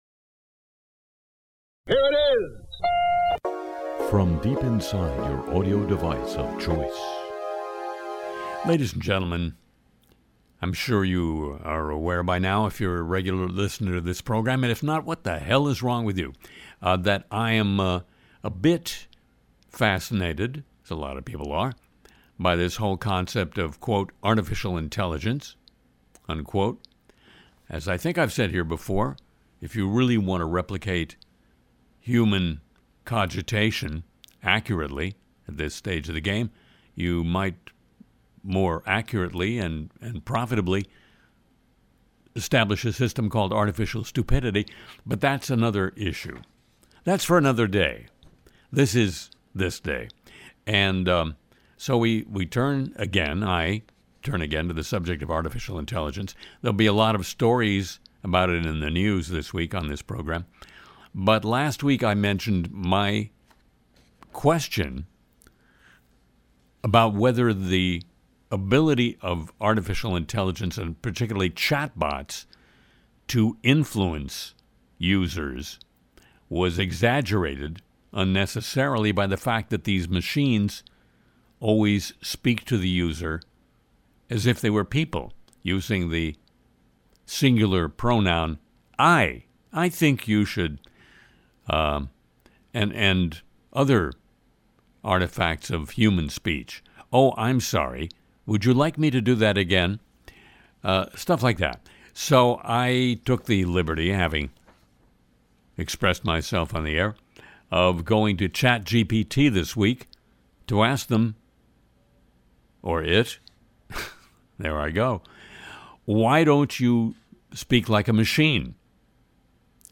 Harry also welcomes a surprise guest, and spins great music.